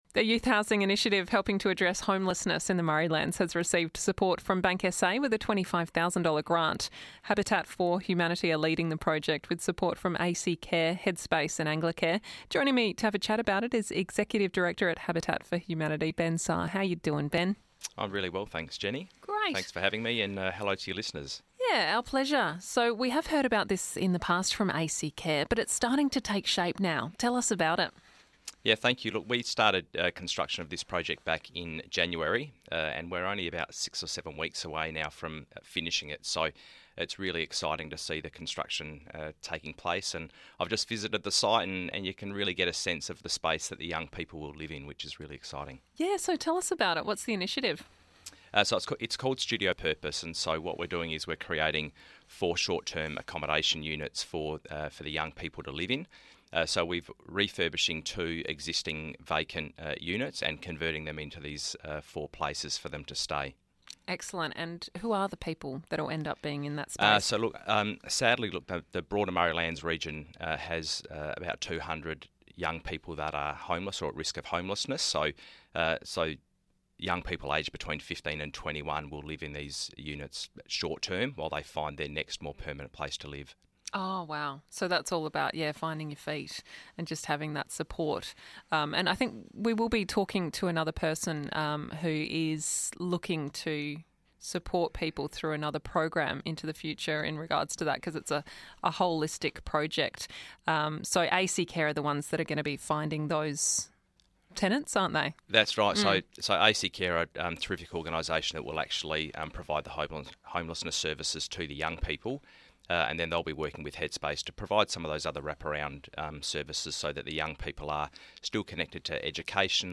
Comments from Minister Corey Wingard on April 30 about the South Eastern Freeway also feature in the audio.